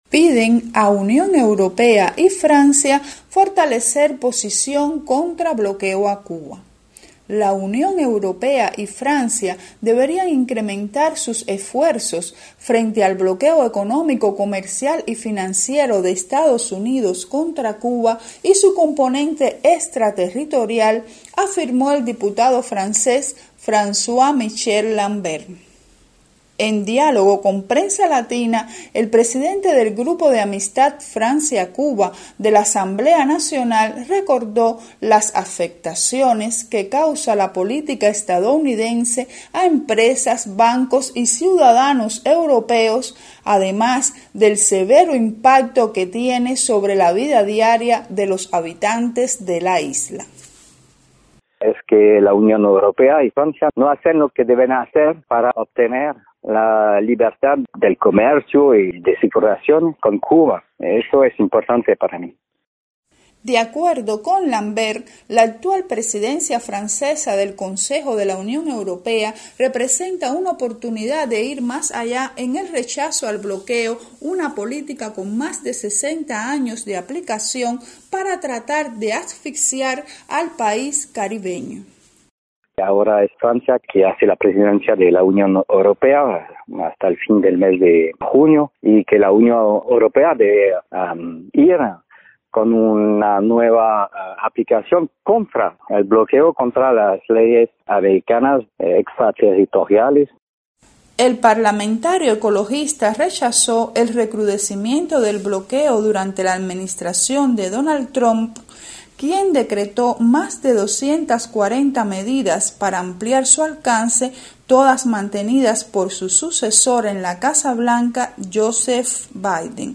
desde París